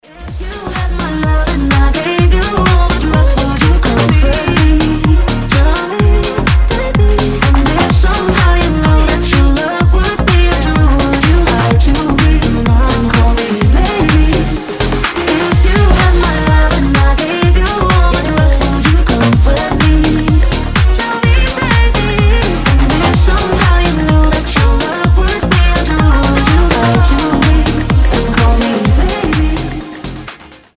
Энергичный клубный рингтон.